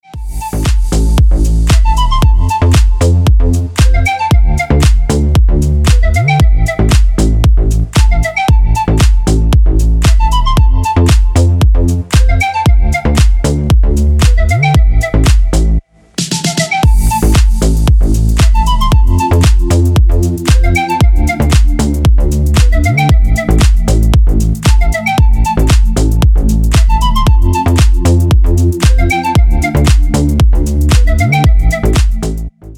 играет Dance рингтоны🎙